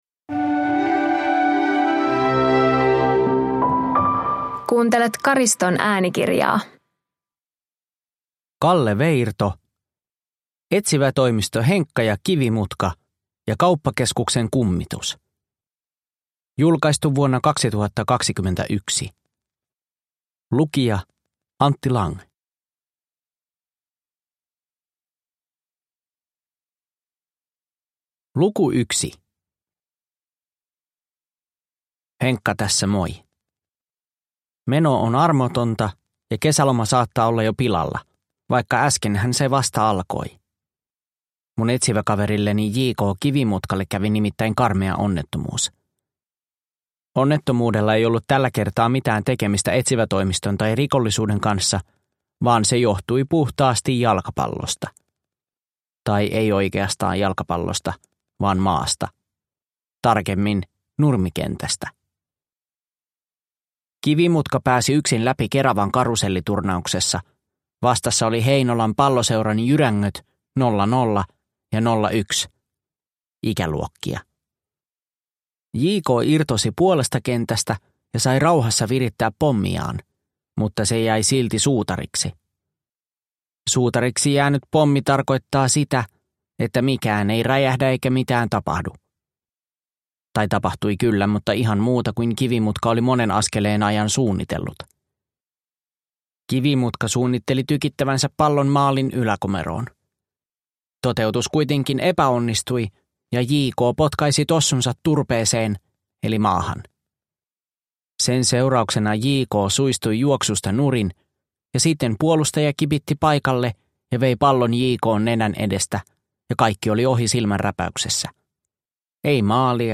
Etsivätoimisto Henkka & Kivimutka ja kauppakeskuksen kummitus – Ljudbok – Laddas ner